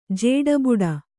♪ jēḍa buḍa